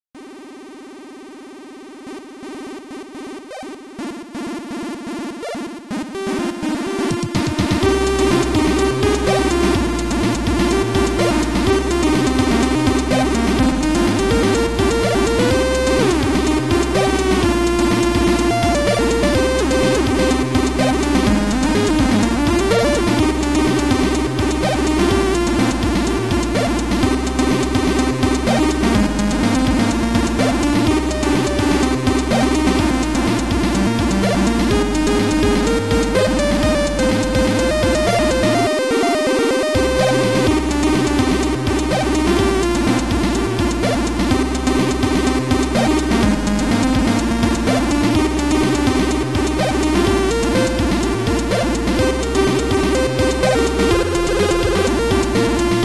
chiptune.mod